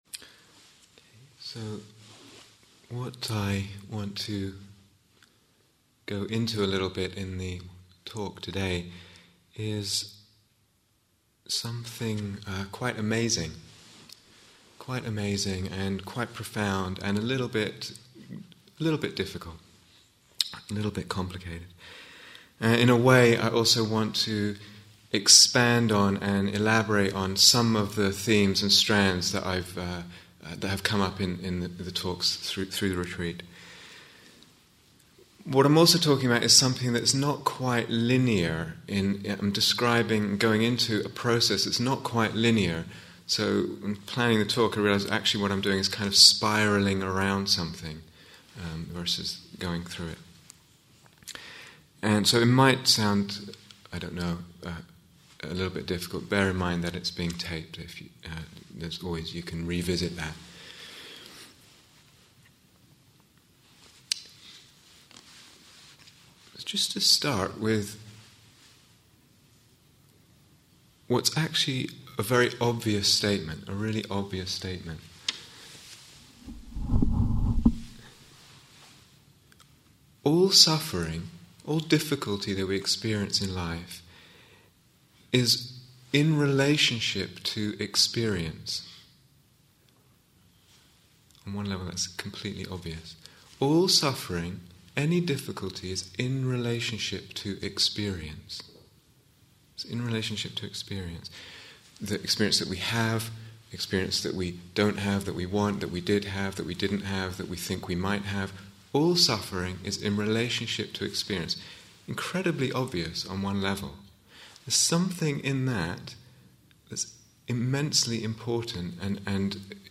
This talk explores some of the Buddha's teaching of Dependent Arising, and how, through right practice, the illusion may be untangled, revealing the true nature of things, of mind, and of Nirvana.